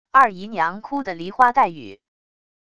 二姨娘哭的梨花带雨wav音频生成系统WAV Audio Player